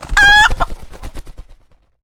chickens and more chickens
taunt1.wav